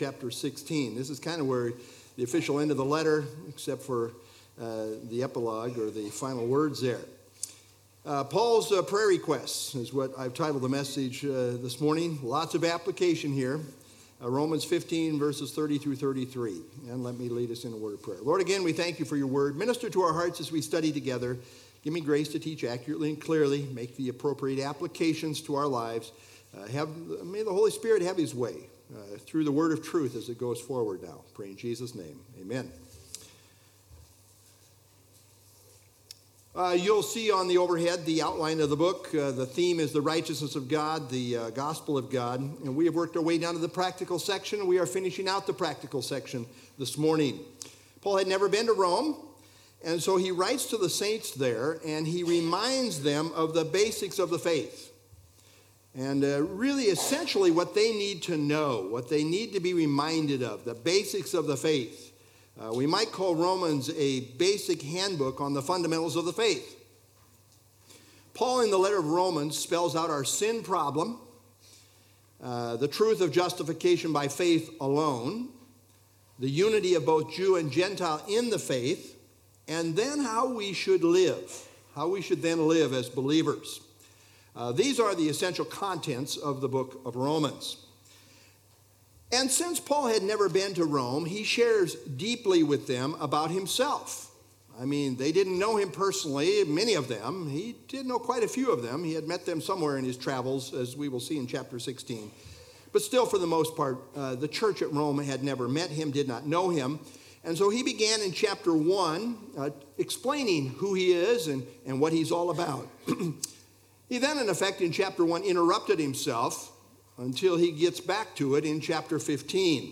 Download FilesRom 15 30-33 Sermon - Dec 15 2024Romans 15_30-33